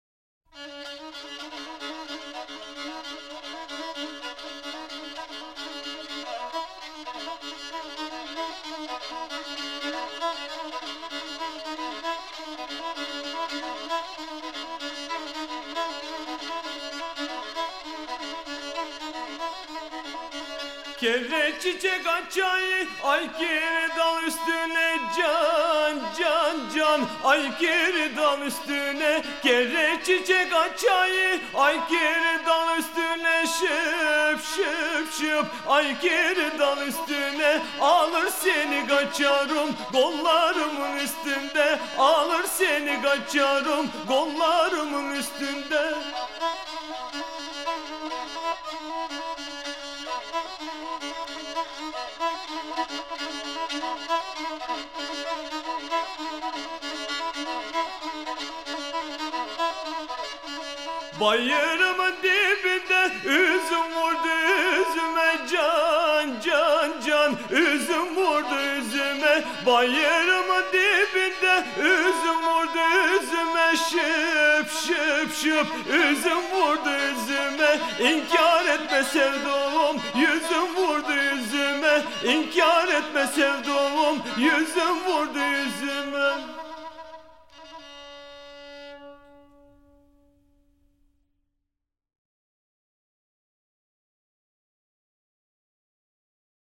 划破黑海长空的民谣歌调
吉普赛风情热力再现
吟遊诗人们以充满吉普赛热力的弹唱，
彩绘出融汇波斯、阿拉伯文化的音乐风情。